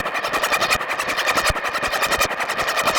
Index of /musicradar/rhythmic-inspiration-samples/80bpm
RI_ArpegiFex_80-03.wav